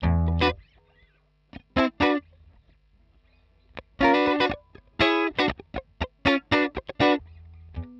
120_Guitar_funky_riff_E_1.wav